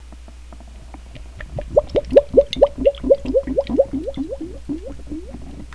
Listen - Gurgles with or without ice.
Crafted of durable stoneware.
gurgle.wav